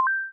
buttonpress.wav